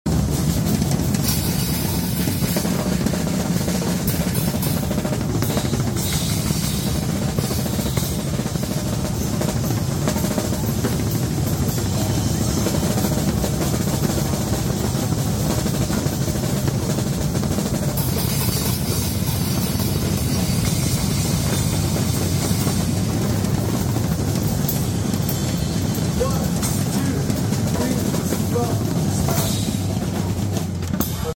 drum100+100, 200 drummers playing all sound effects free download
200 drums solos!!!🥁💥🔥